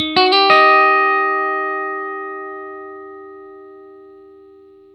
RIFF1-120EF.wav